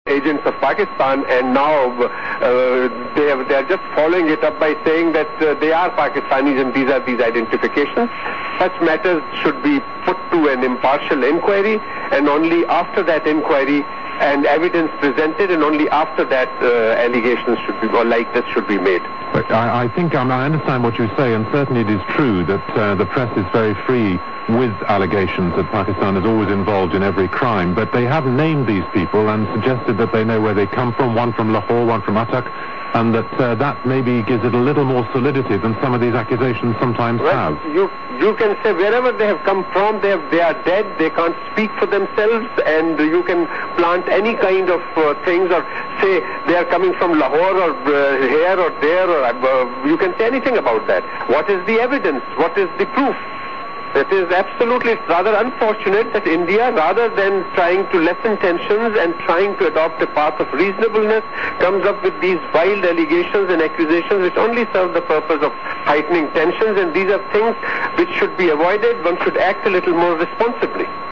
In its phonology English in both Pakistan and India is remarkable for its intonation and for the retroflexion of consonants (pronouncing the alveolar consonants of English with the tip of the tongue curved back towards the palate).
Furthermore, one should mention that English in Pakistan and India is rhotic and that vowels show much less diphthongisation than in southern British English.
Pakistani_English.wav